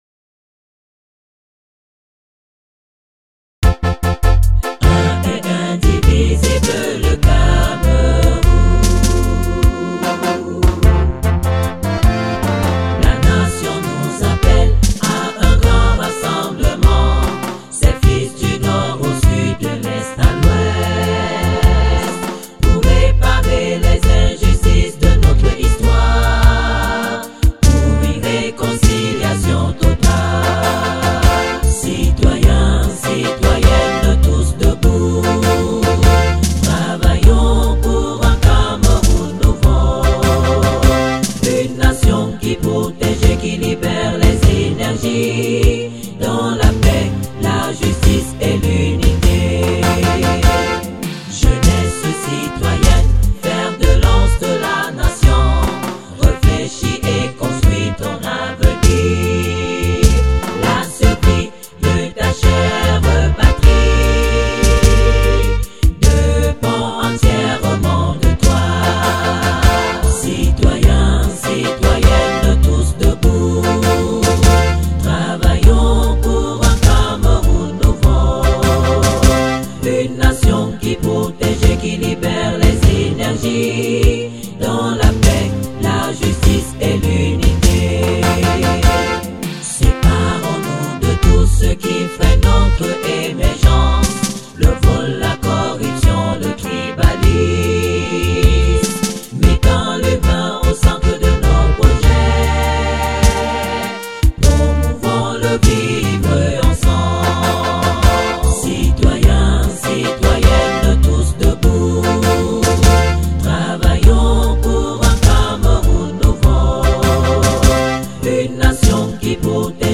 HYMNE DU PCRN en français (Vocal)
HymnePCRNFrVocal.mp3